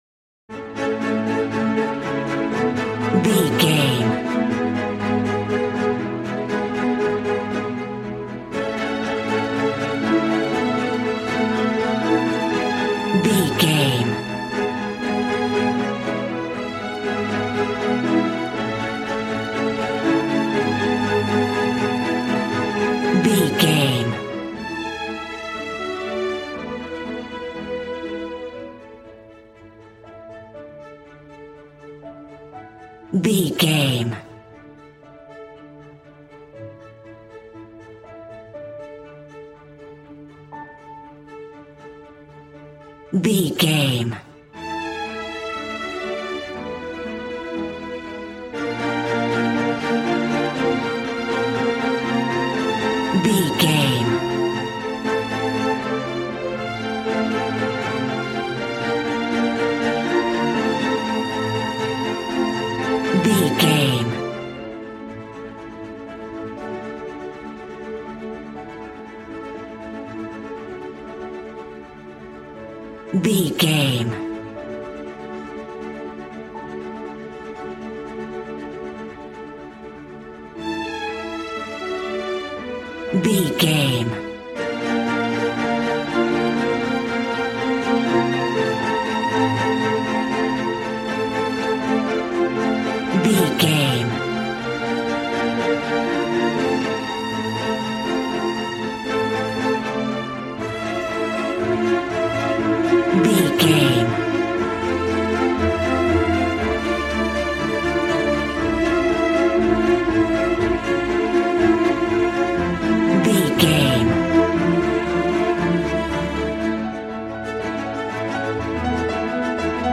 Regal and romantic, a classy piece of classical music.
Ionian/Major
regal
cello
violin
strings